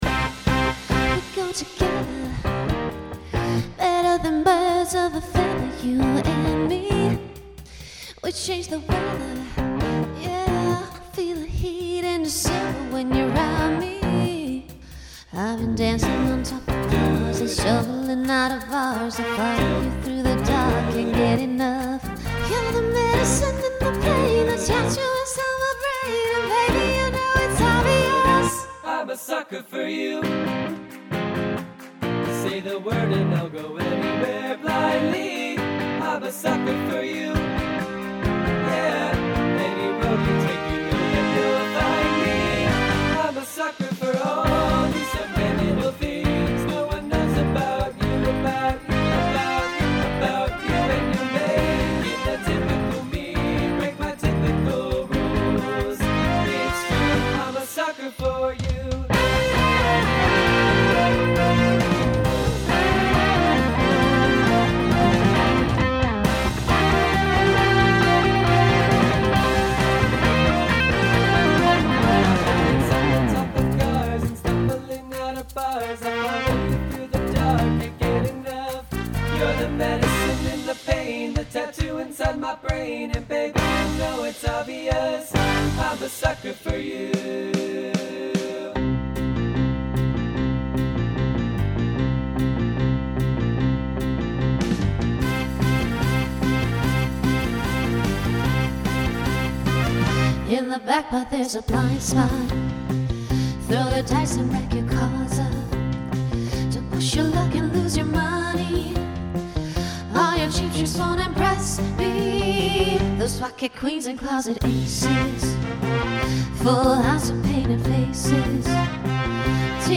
TTB with female solo/SSA/SATB
Genre Pop/Dance , Rock
Transition Voicing Mixed